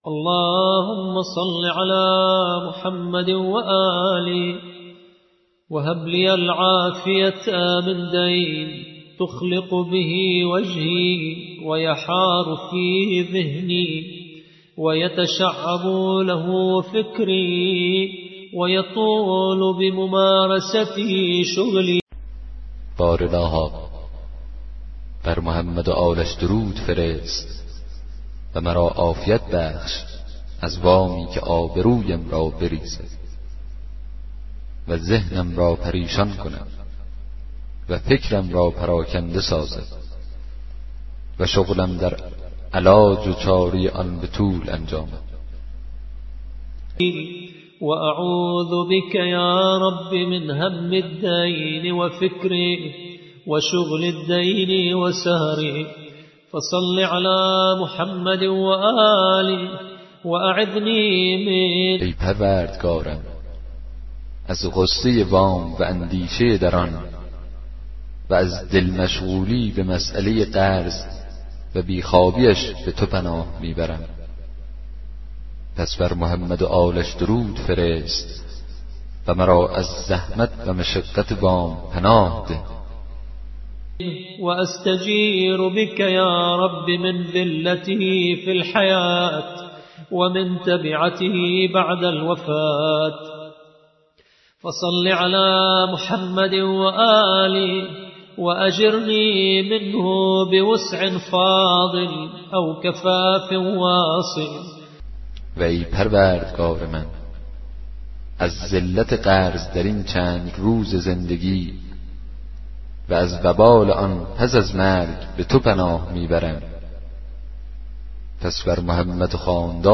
کتاب صوتی دعای 30 صحیفه سجادیه
به همراه ترجمه فارسی